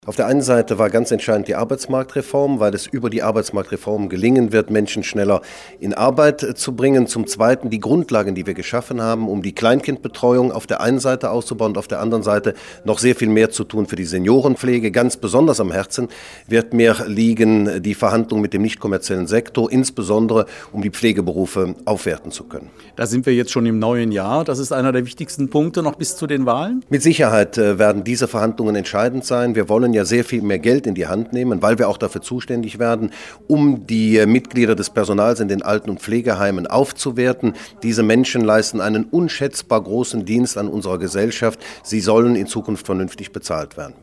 hat mit allen vier Ministern gesprochen. Starten wollen wir mit Ministerpräsident Oliver Paasch.